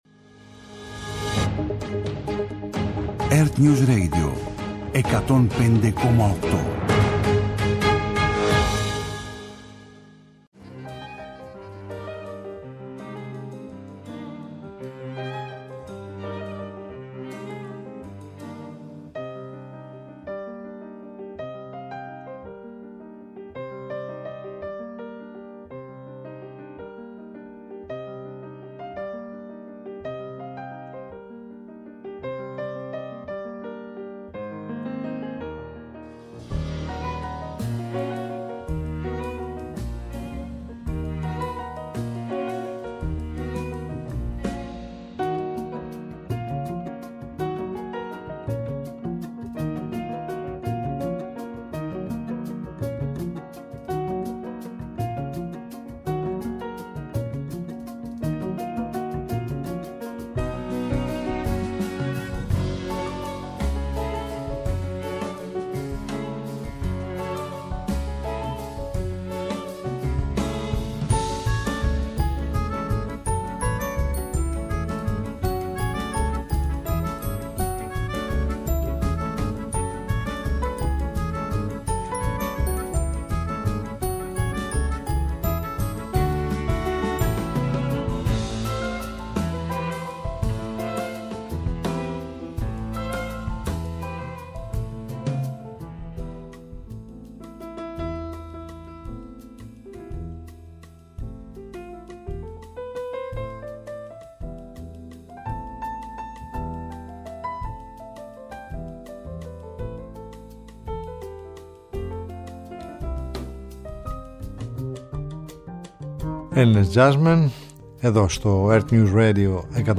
επιλογές από την ελληνική jazz σκηνή